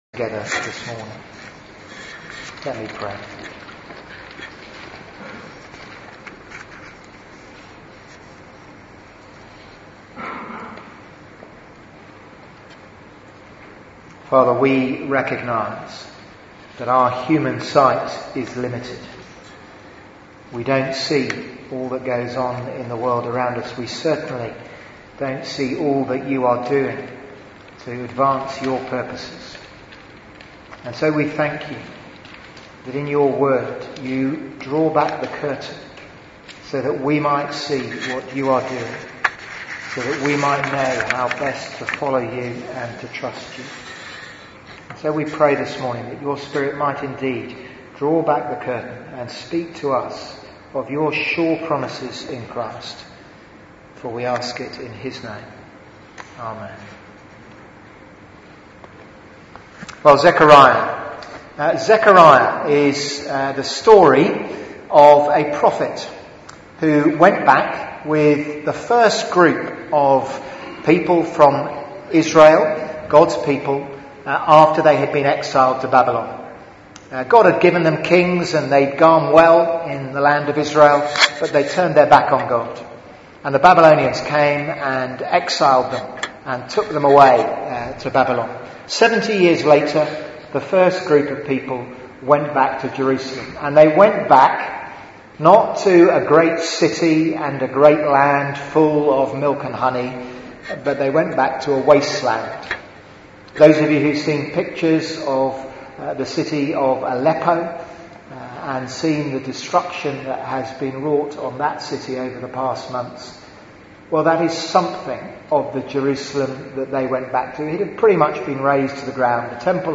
Media for 11am Service on Sun 04th Dec 2016
The man with the measuring line Sermon